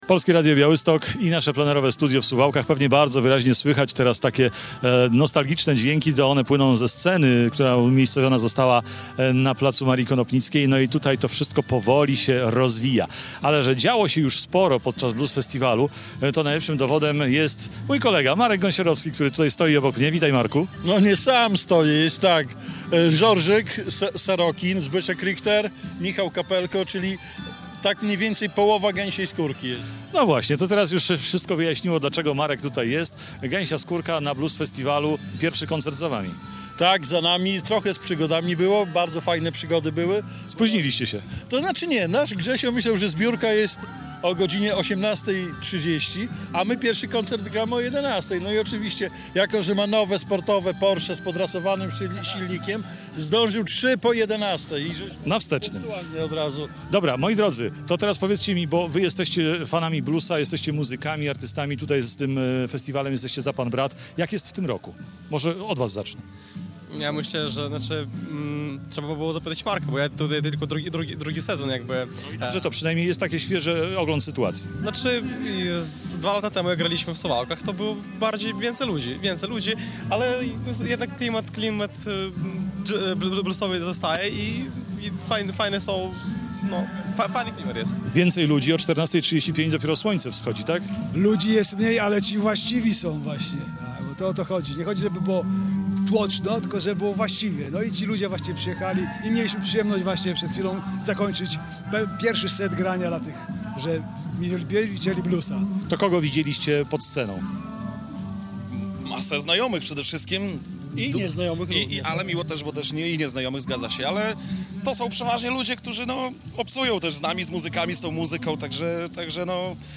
Z wiceprzewodniczący sejmiku województwa podlaskiego Romualdem Łanczkowskim rozmawia
Plenerowe studio Radia Białystok stanęło nieopodal sceny na Placu Marii Konopnickiej